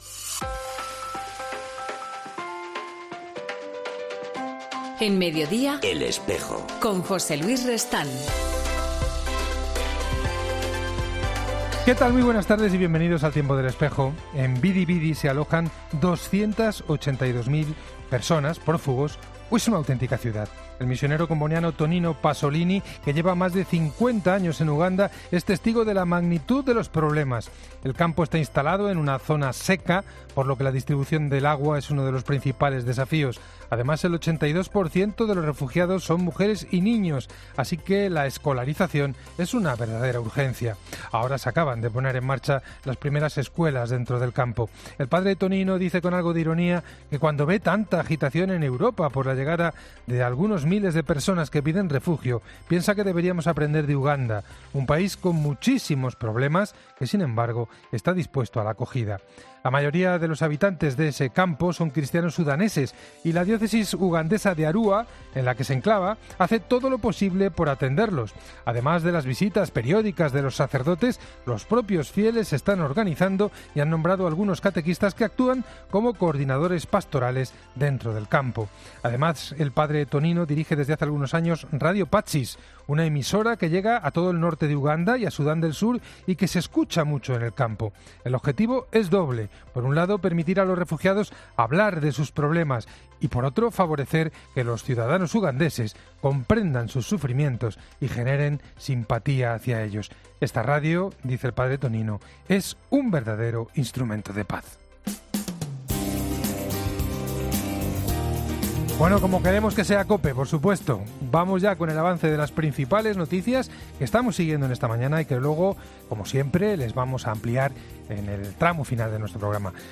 En 'El Espejo' del lunes, 3 de septiembre de 2018 hablamos con José María Gil Tamayo, secretario general y portavoz de la Conferencia Episcopal Española.